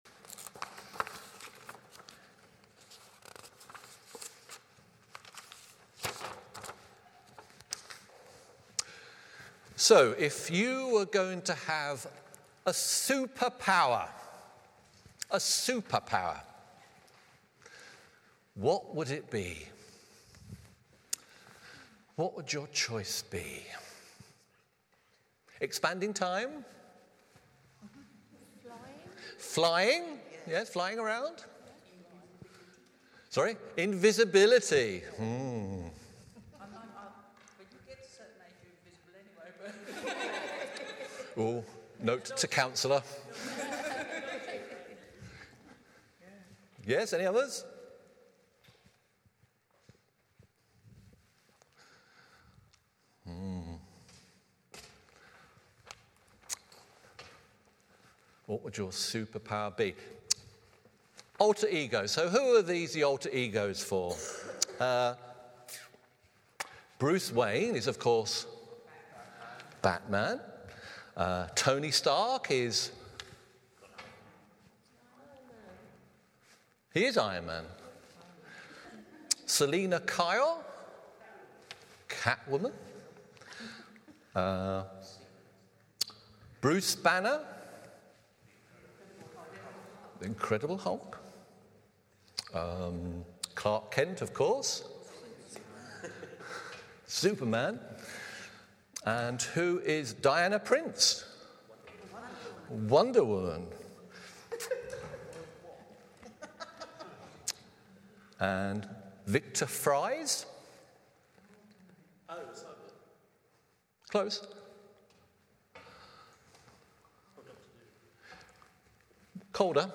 Series: Who is God? Passage: Isaiah 26:1-11 Service Type: Morning Service « Does God ever change?